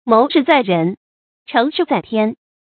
móu shì zài rén，chéng shì zài tiān
谋事在人，成事在天发音